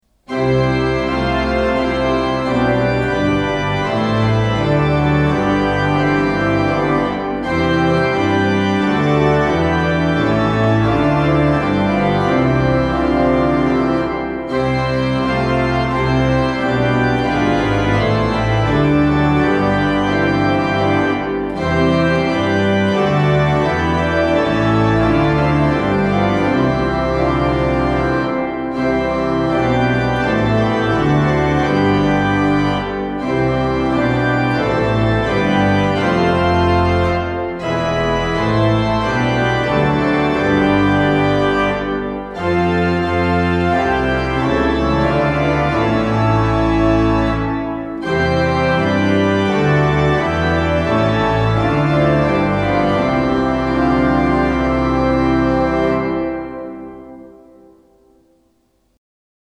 "내 주는 강한 성", 등음형 선율
등음형 선율의 오르간 편곡